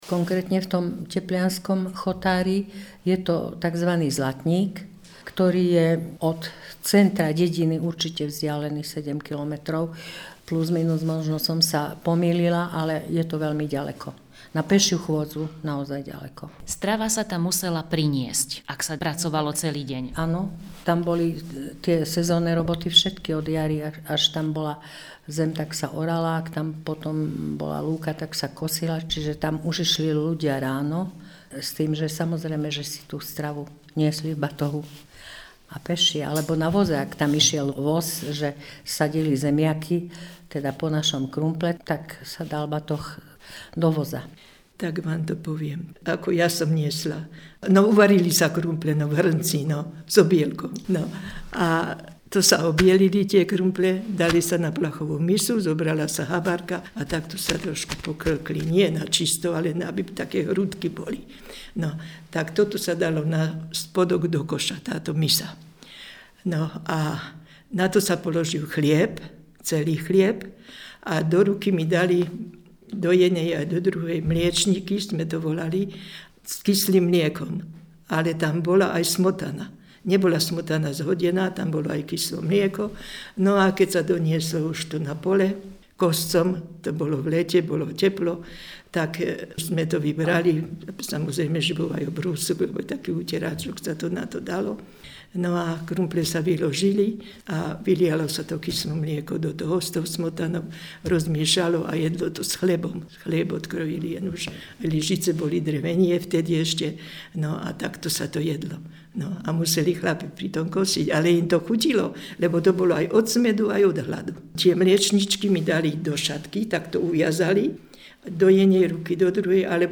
1. Popis 1. spomienkové rozprávanie o prenášaní stravy na polia a lúky v obci Podhorie
Poznámky spomienkové rozprávanie
Miesto záznamu Podhorie